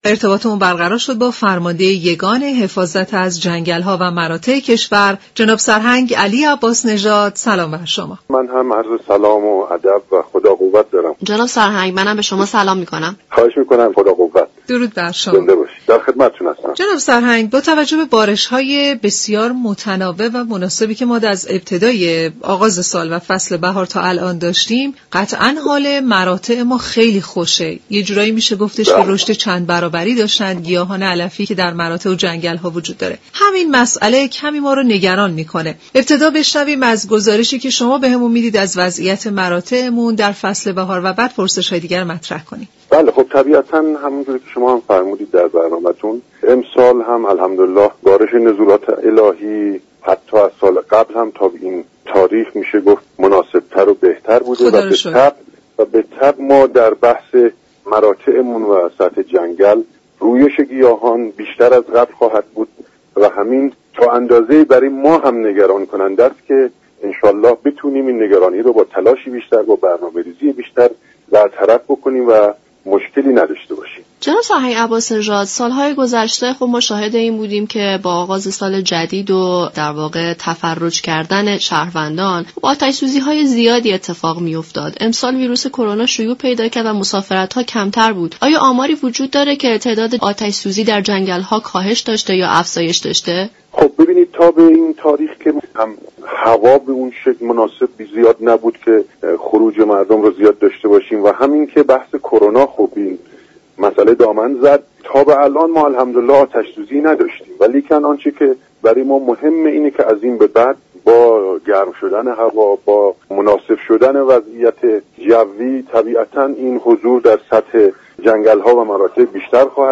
به گزارش شبكه رادیویی ایران، جناب سرهنگ علی عباس نژاد فرمانده یگان حفاظت از جنگل ها و مراتع كشور در برنامه سیاره آبی درباره وضعیت مراتع كشور در بهار امسال گفت: خوشبختانه میزان بارش های كشور در بهار امسال از سال گذشته نیز بهتر بوده و این بارش ها باعث رویش گیاهان در مراتع شده است.